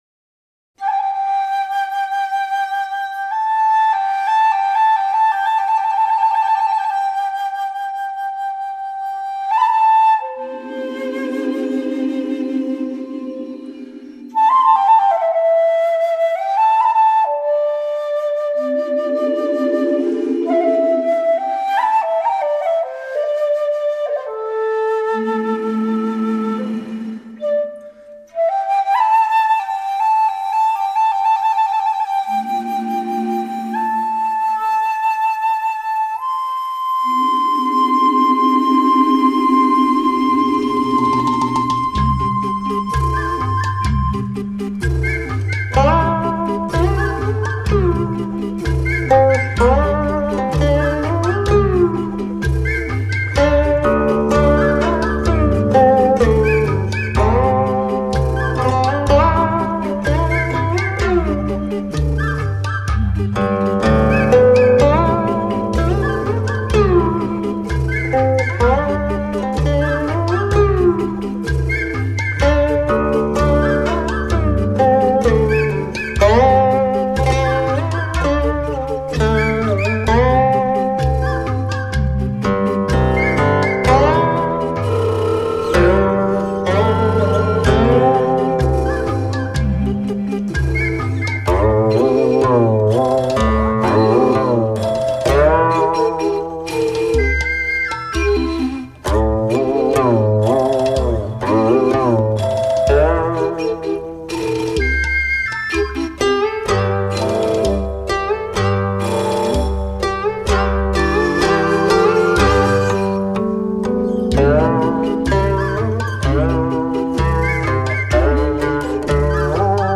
古色古香，很别致的演奏
埙是个什么乐曲啊，很有厚重感啊，谢谢大大的分享